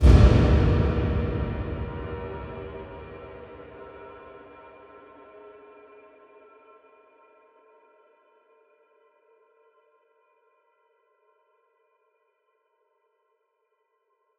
15_TrapFX_SP_03.wav